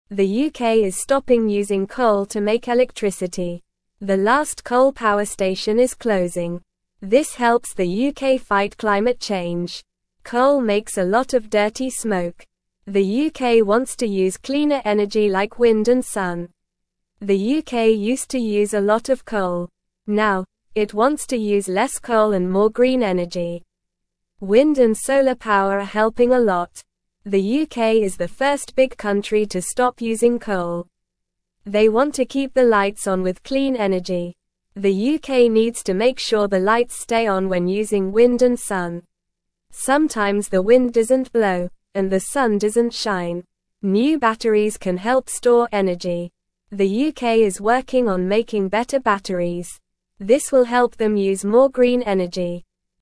Normal
English-Newsroom-Beginner-NORMAL-Reading-UK-Stops-Using-Coal-for-Electricity-Fights-Climate-Change.mp3